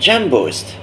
pusuperjumpvoice.wav